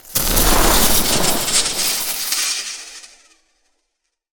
elec_lightning_magic_spell_06.wav